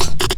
10 LOOPSD1-L.wav